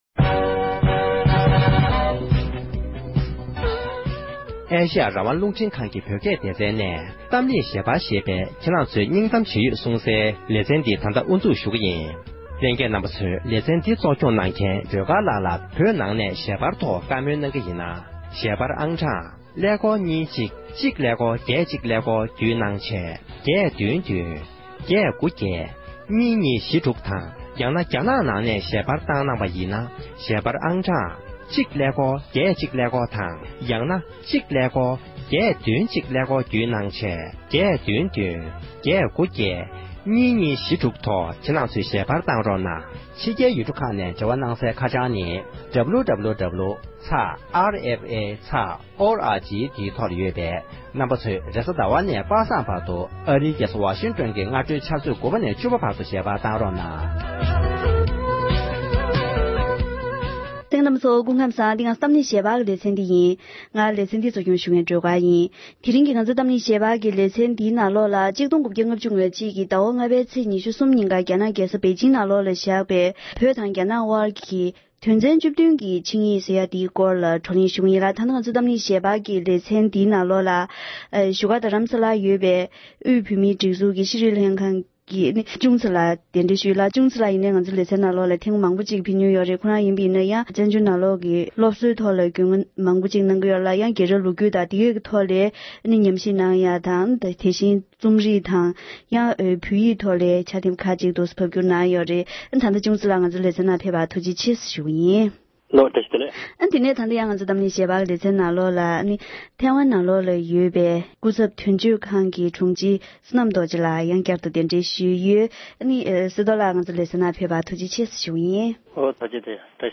ལོ་ངོ་དྲུག་བཅུ་སྔོན་བཞག་པའི་བོད་དང་རྒྱ་ནག་དབར་གྱི་ཆིངས་ཡིག་དོན་ཚན་བཅུ་བདུན་གྱི་རྒྱབ་ལྗོངས་ཐད་བགྲོ་གླེང༌།